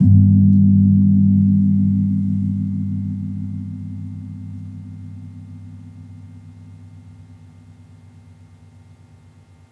Gong-G1-p.wav